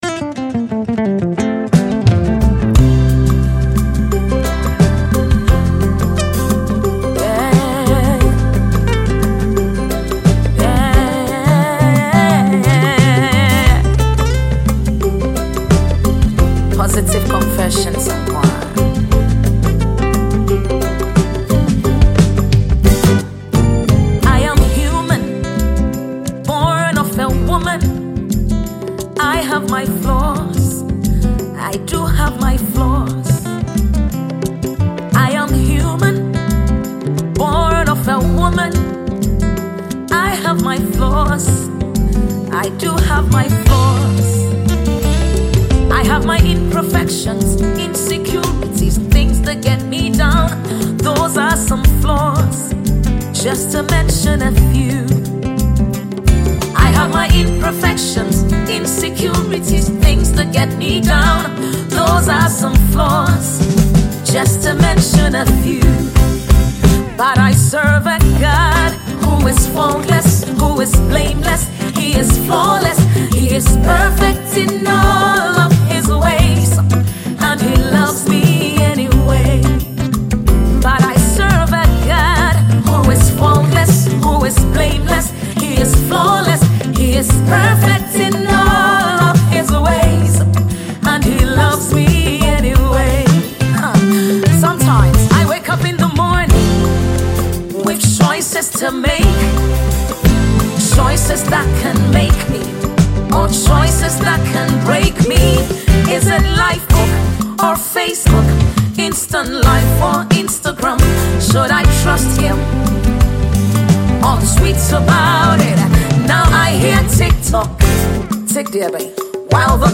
Multiple award-winning Ghanaian gospel musician